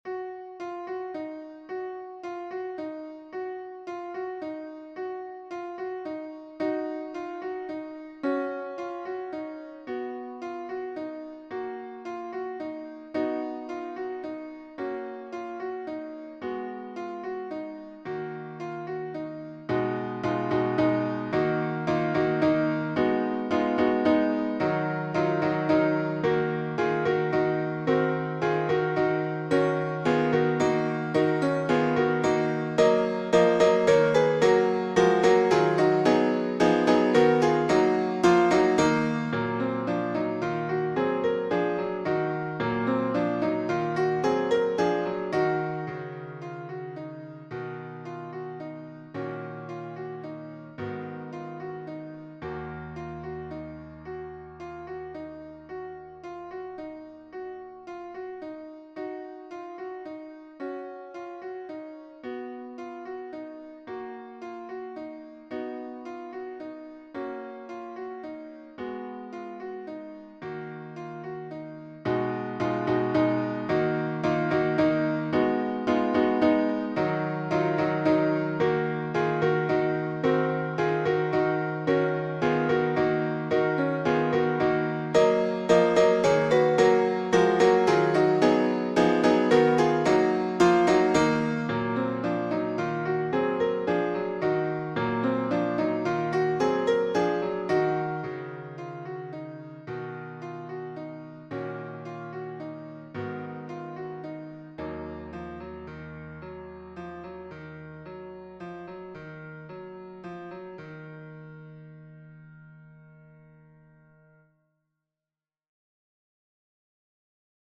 MP3 version piano